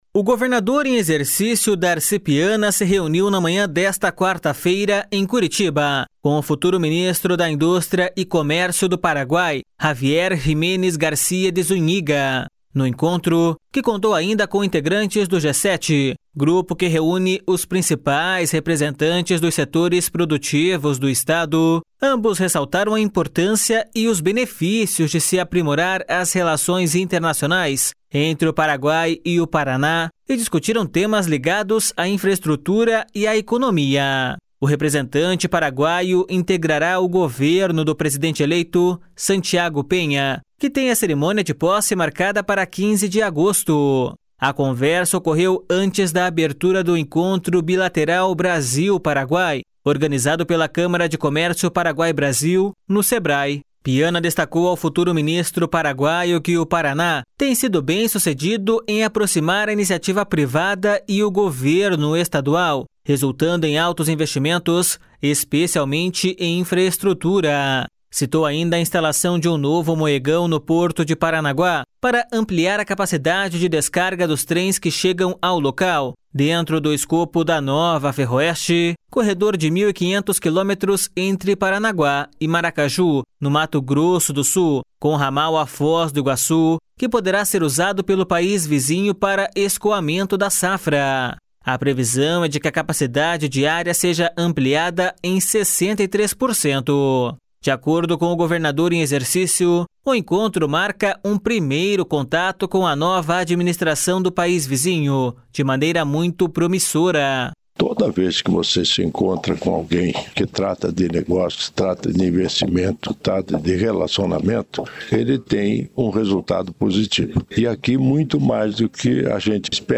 De acordo com o governador em exercício, o encontro marca um primeiro contato com a nova administração do país vizinho de maneira muito promissora.// SONORA DARCI PIANA.//